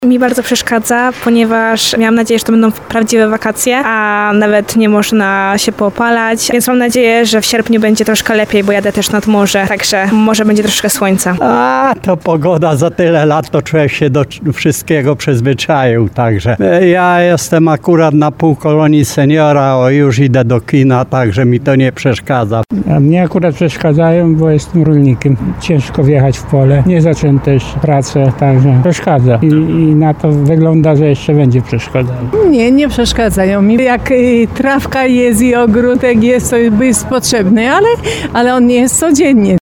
Niektórzy mieszkańcy Tarnowa i regionu przyznają, że liczyli w te wakacje na lepszą pogodę.
28sonda_deszcz.mp3